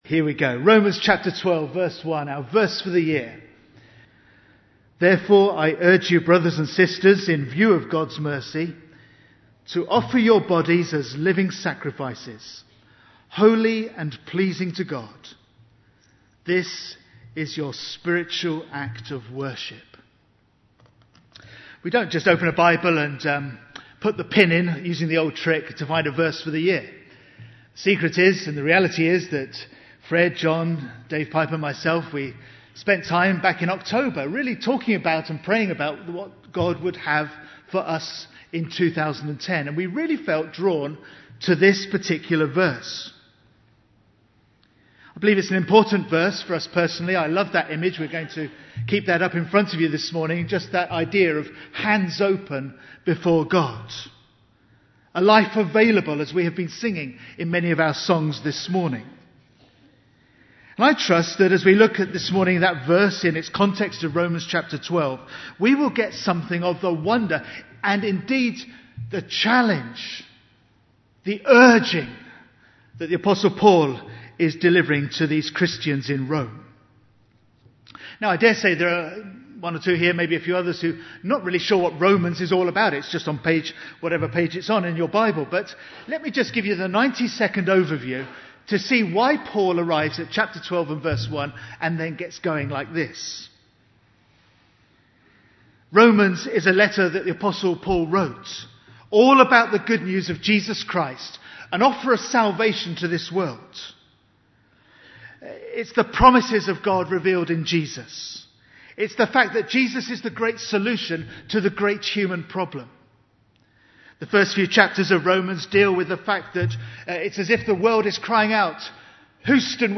Bible Text: 1 Peter 1:1-12 | Preacher